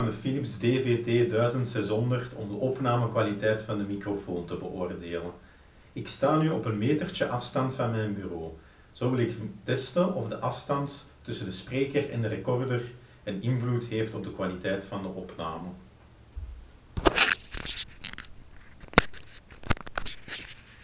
First in a closed room, then in the same room but with the windows open, and then from a meter away from the microphone.
Audio fragment 3 (distance from desk)
The pen captures our voice well, although it is advisable to keep the recorder nearby.